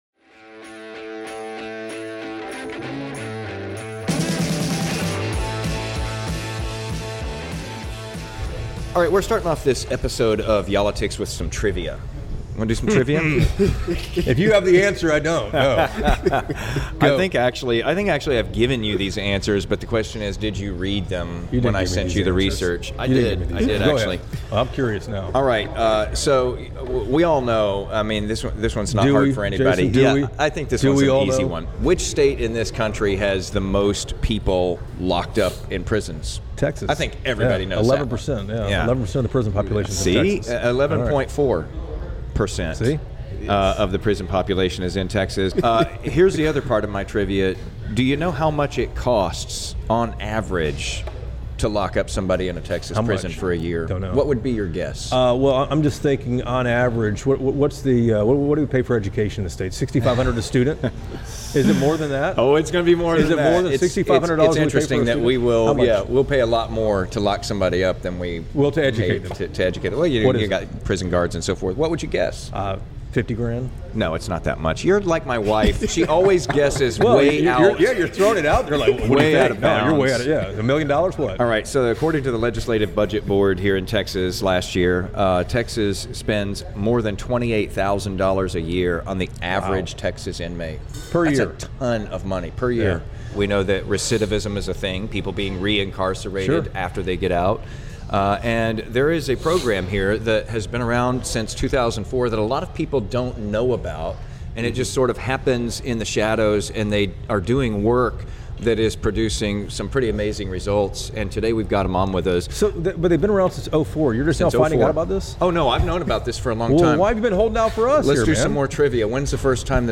Yall-itics doesnt come from a fancy studio. Were taking our podcast on the road to get past the soundbites and dive deeper into the issues that matter to yall.